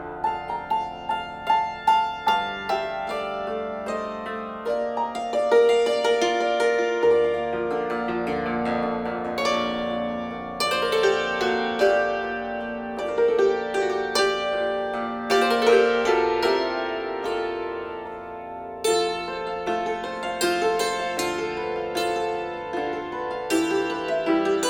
Hackbrettquartett